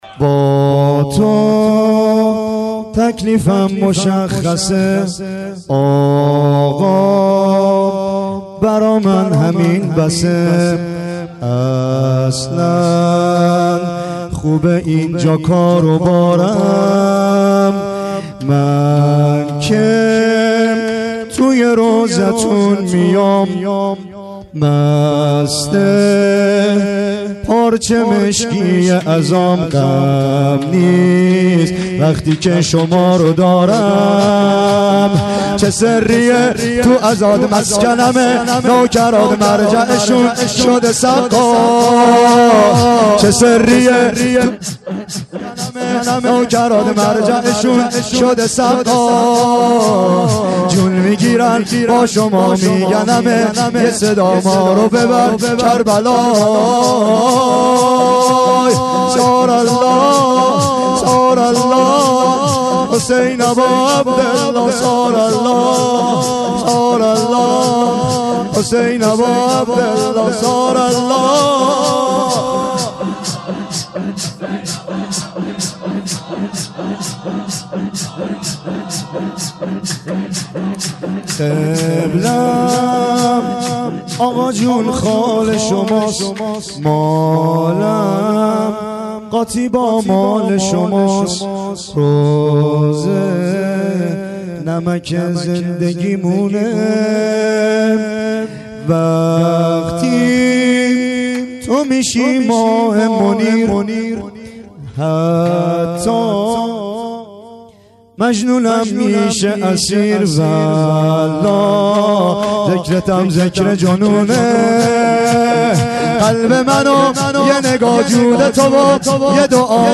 با تو تکلیفم مشخصه(سینه زنی/شور